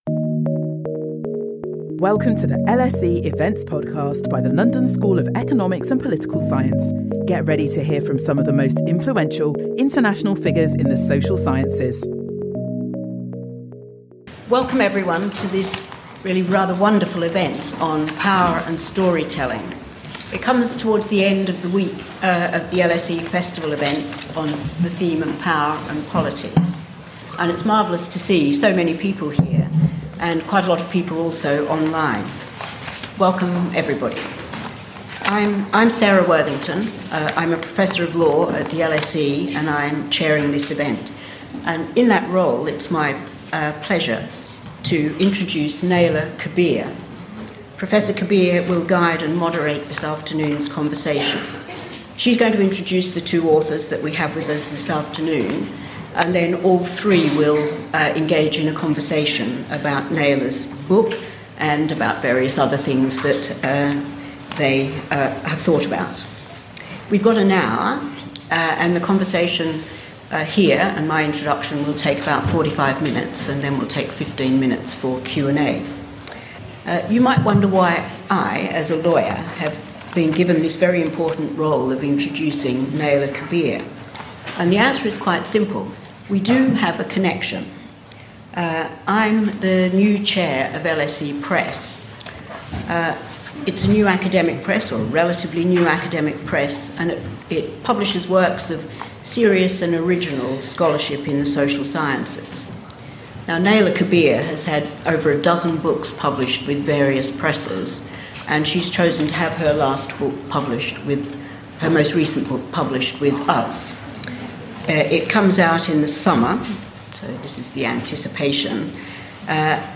LSE Press author, Naila Kabeer, launches her new book in conversation with Monica Ali and Philip Hensher, exploring the purpose and value of different narrative forms, as well as considering the impact of literature on global communities.